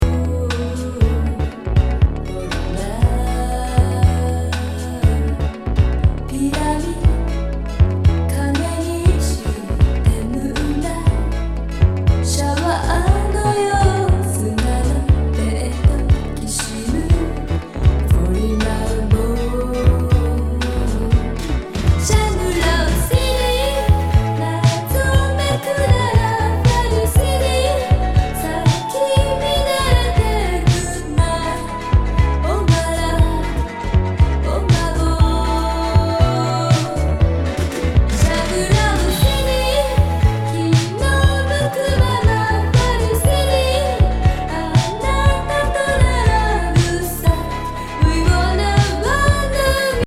アンビエンス・ポップ